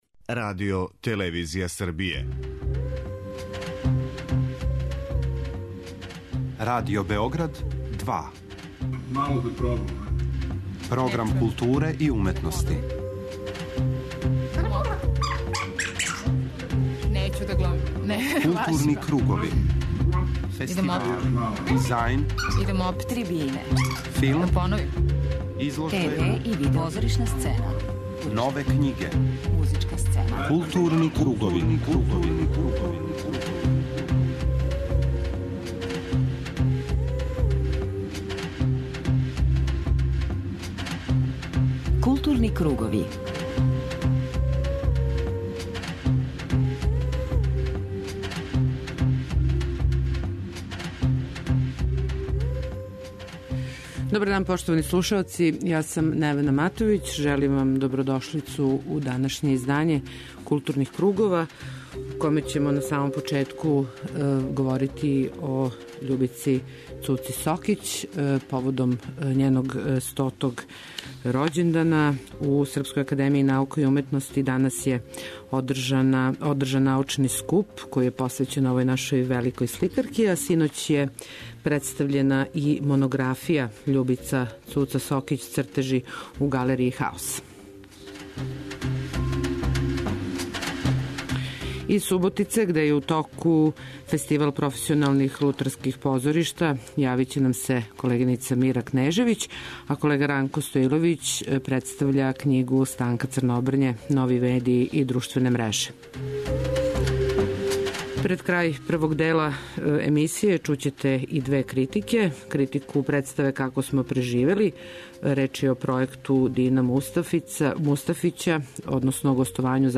Филмораму ћемо започети извештајем са данашње презентације најмодерније биоскопске технологије, 48 high frame rate-а. Први филм који ће бити приказан у 48ХФР-у ће бити Хобит: Битка пет армија, Питера Џексона, снимљен у 3D техници, који у биоскопе у Србији долази 11. децембра.